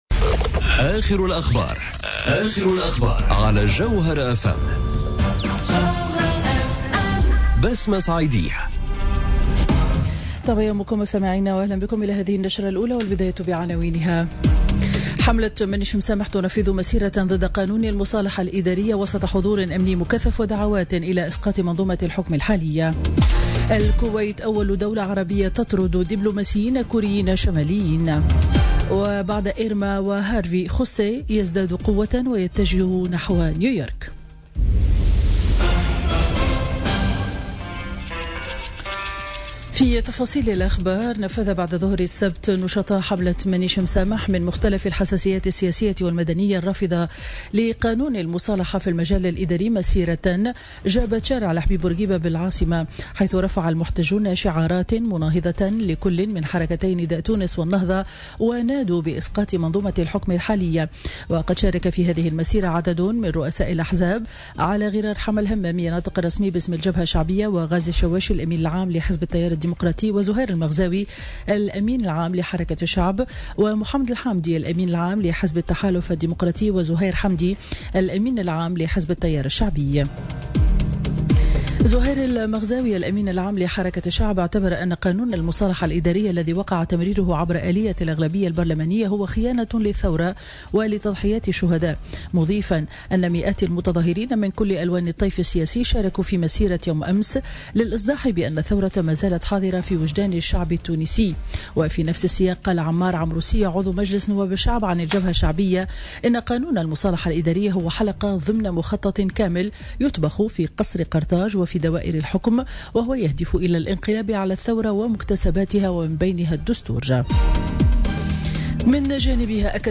نشرة أخبار السابعة صباحا ليوم الأحد 17 سبتمبر 2017